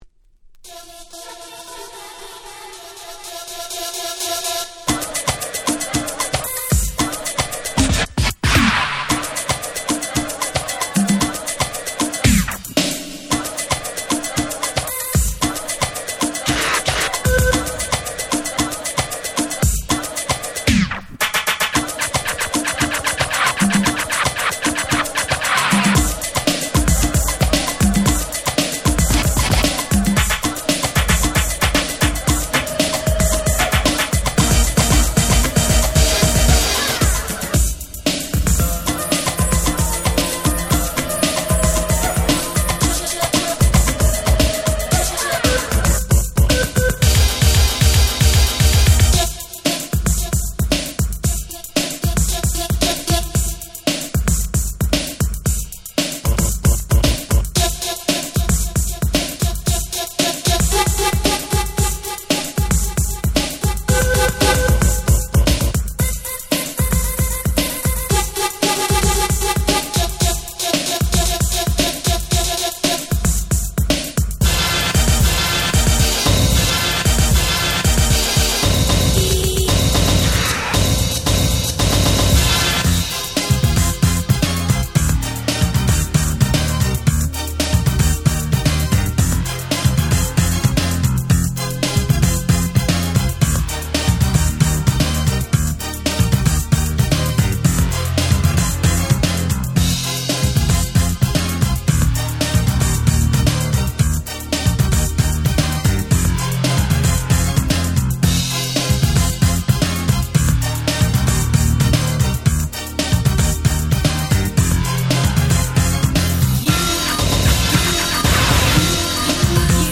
88' Super Hit Disco / R&B !!